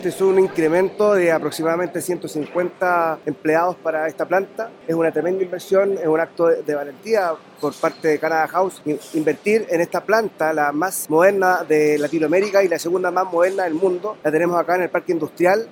El alcalde de la comuna, Ricardo Jaramillo, destacó que la iniciativa ha generado más de 160 empleos y subrayó cómo esta inversión posicionará estratégicamente a Lautaro para el futuro.
alcalde-de-lautaro.mp3